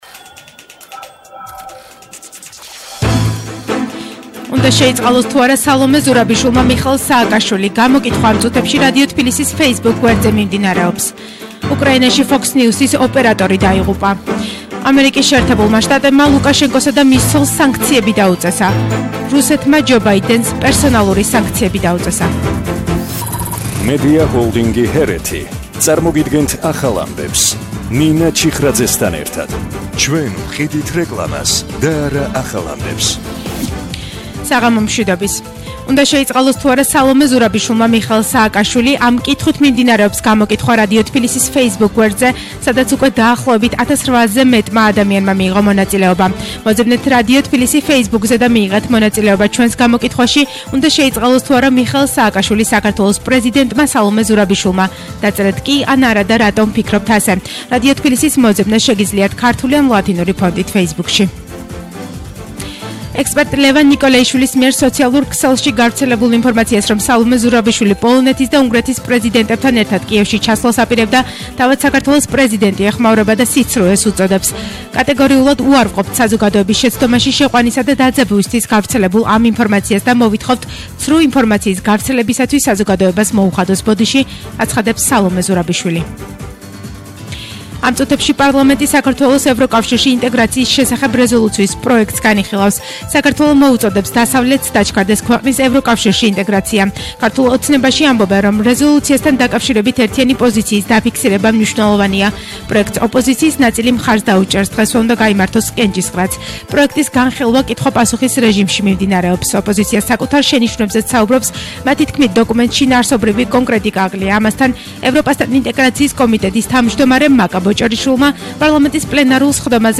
ახალი ამბები 20:00 საათზე – 15/03/22